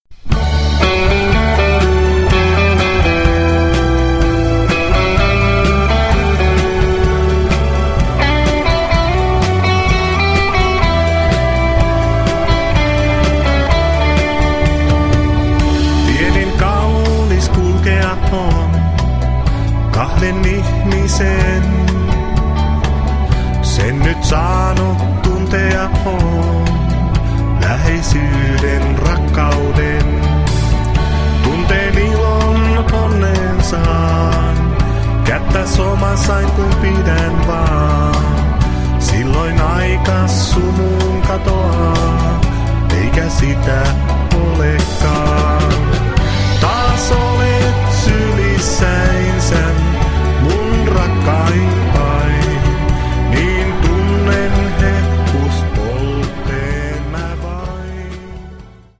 perinteistä tanssimusiikkia
Rummut, laulu
Kitara, laulu
Kosketinsoittimet, haitari, laulu